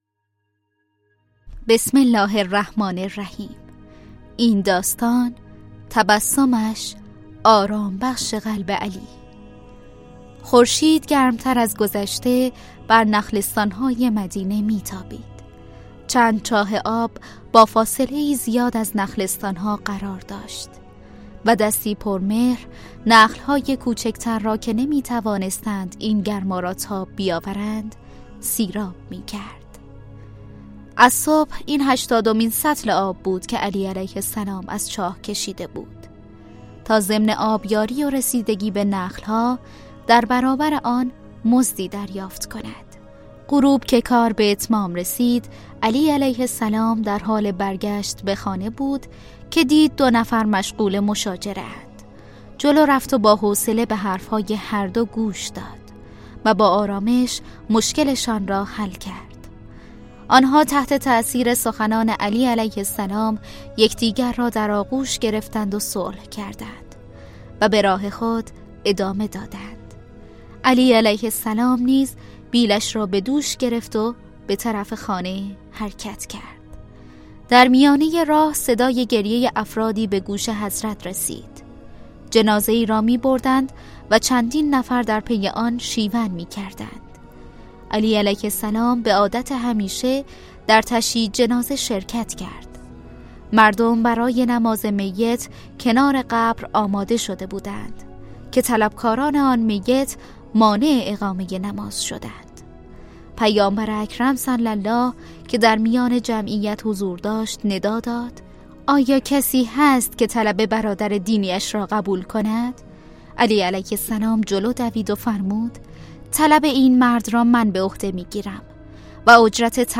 کتاب صوتی مهربانو